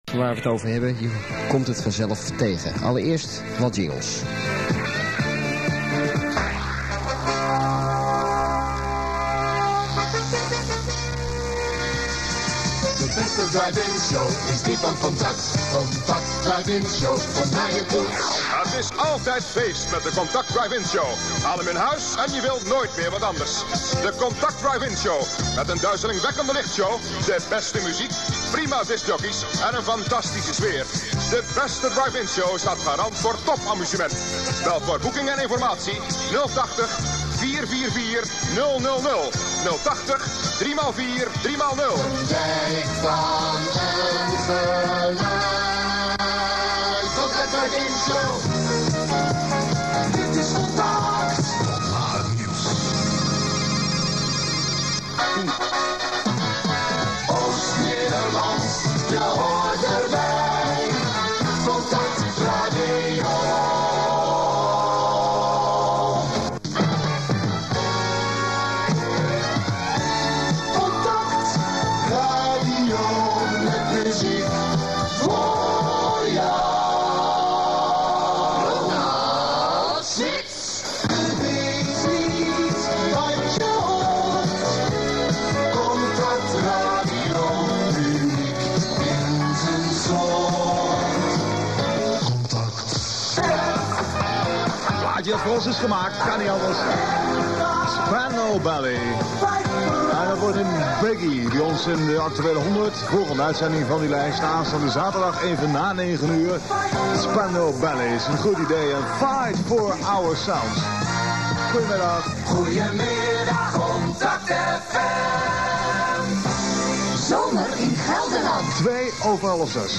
Diversen Contact aircheck 1 8,1 MB